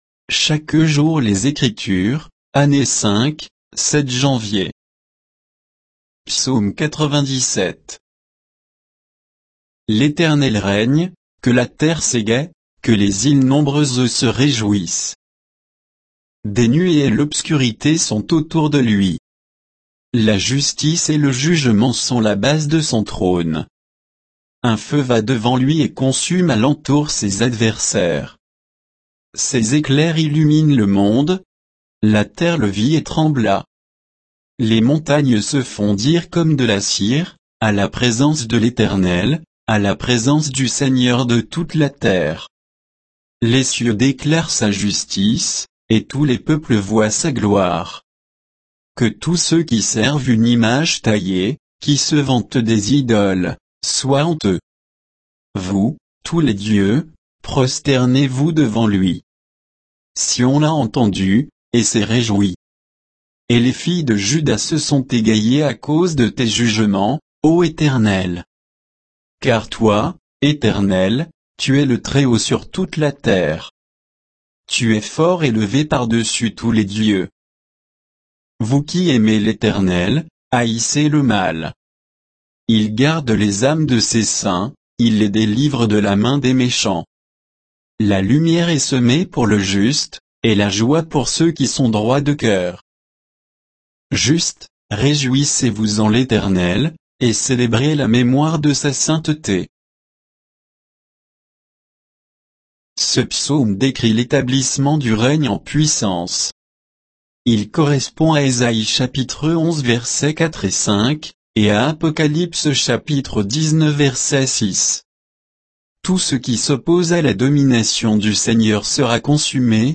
Méditation quoditienne de Chaque jour les Écritures sur Psaume 97